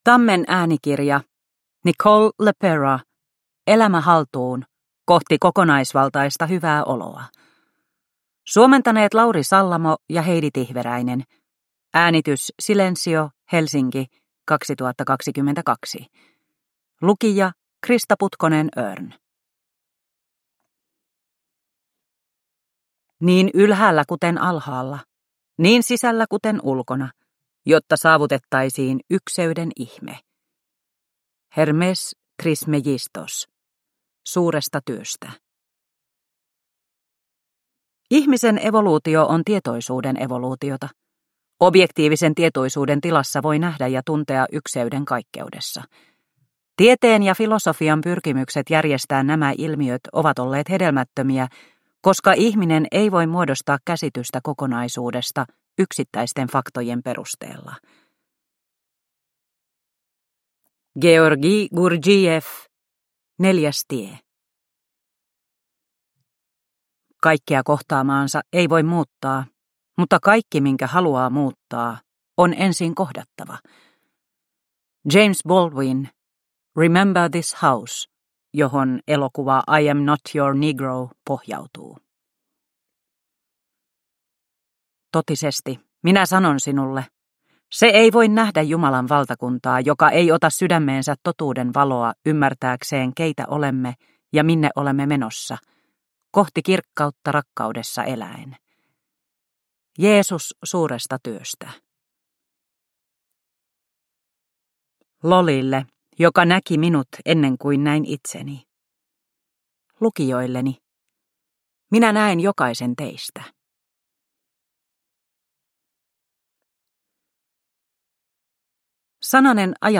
Elämä haltuun – Ljudbok – Laddas ner